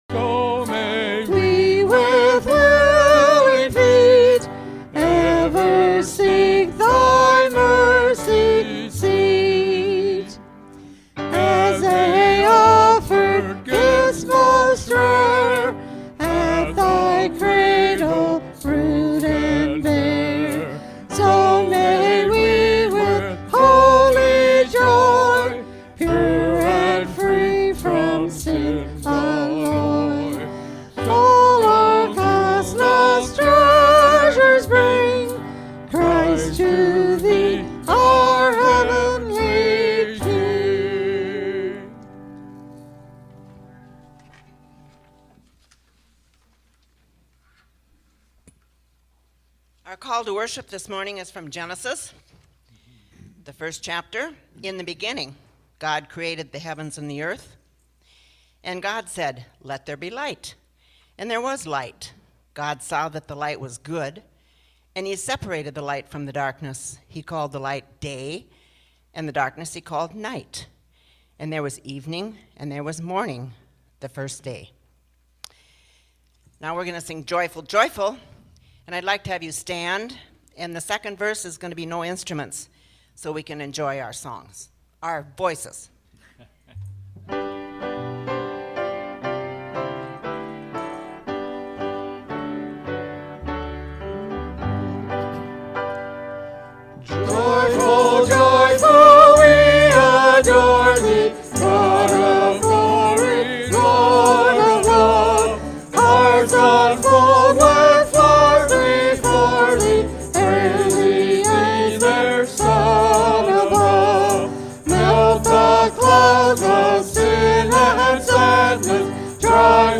Worship_-January-7_-2024-Voice-Only.mp3